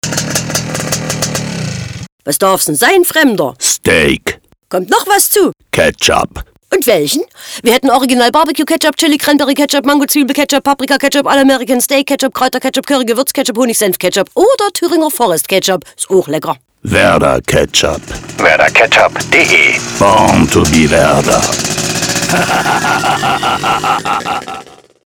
Nach seinem akustischen Erscheinungsbild zu urteilen, ist er ein Typ Easyrider.
04-Nur-SpracheEffekteMoped.mp3